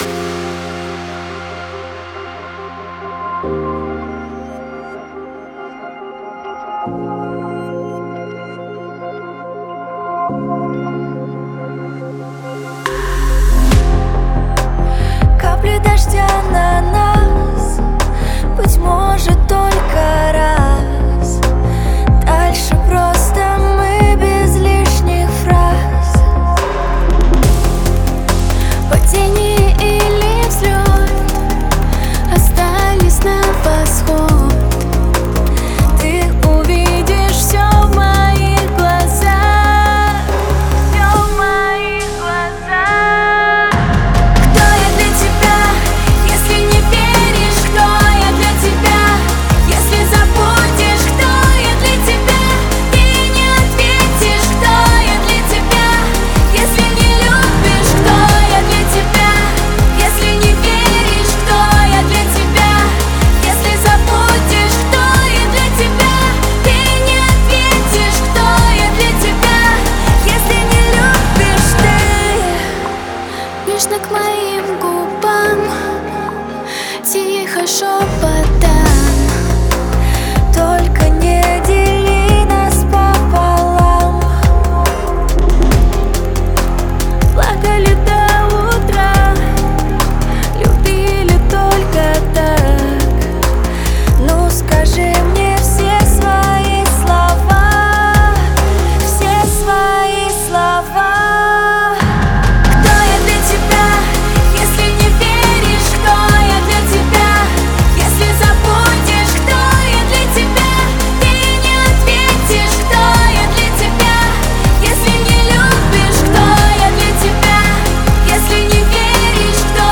Драм-н-басс